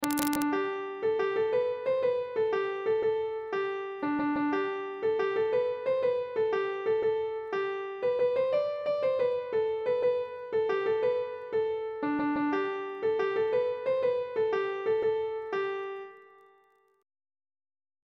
Air.